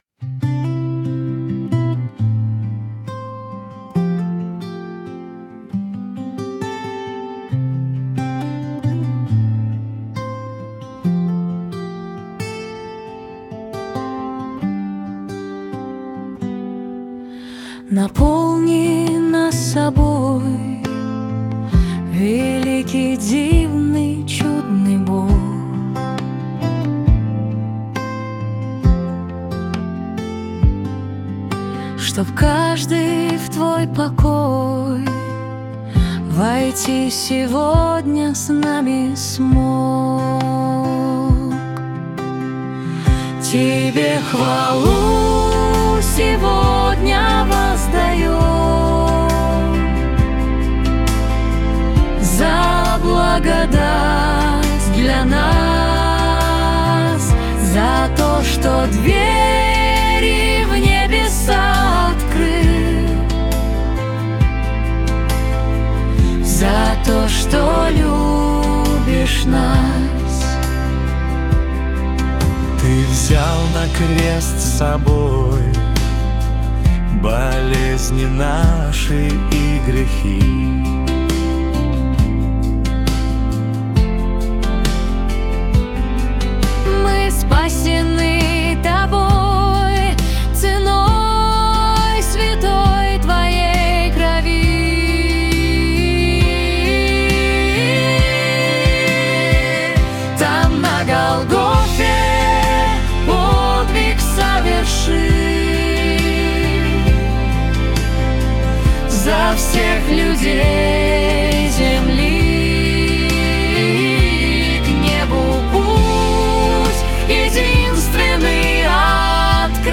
песня ai
Вокал и аранжировка  ИИ